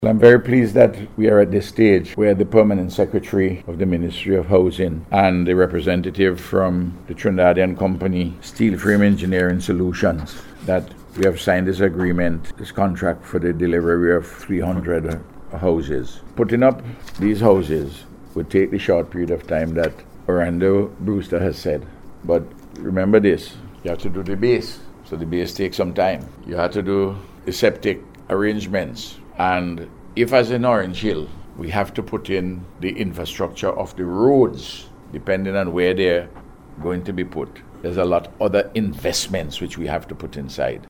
He made this statement yesterday during a press briefing which was held for the signing of a contract with the company, for the procurement of the houses.